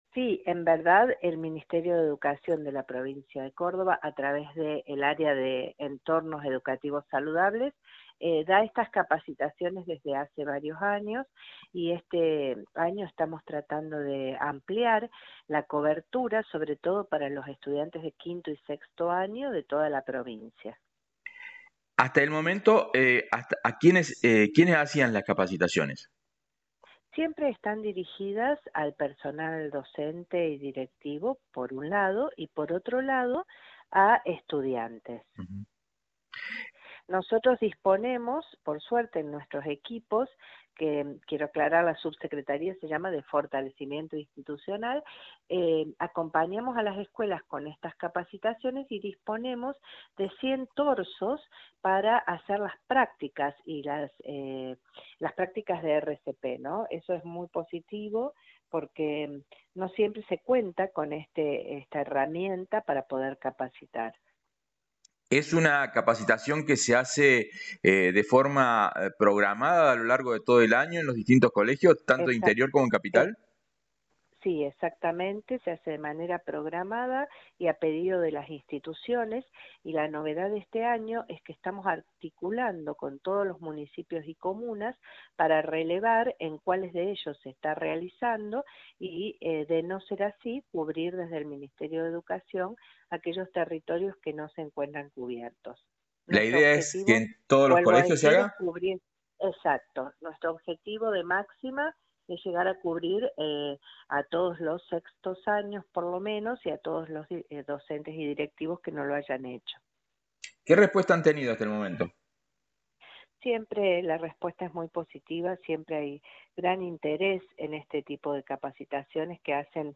Audio: Claudia Maine (Subsecretaria de Fortalecimiento Institucional – Min. Educ. Cba.)